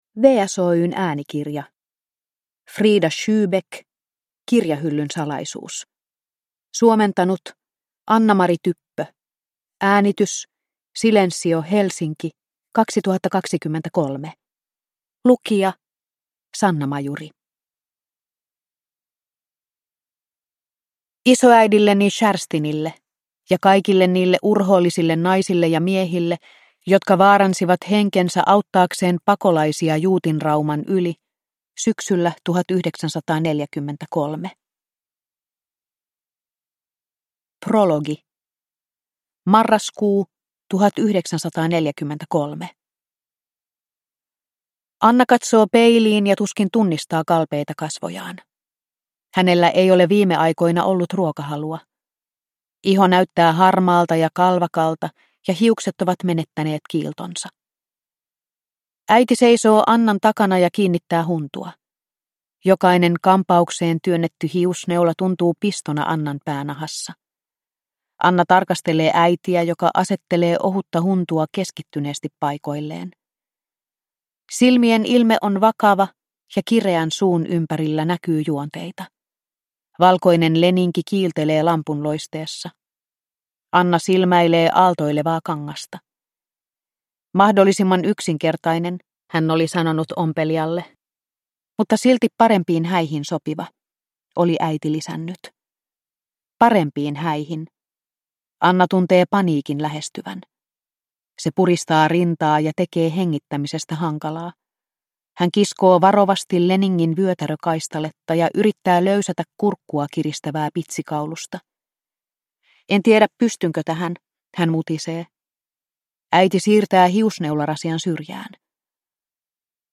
Kirjahyllyn salaisuus – Ljudbok – Laddas ner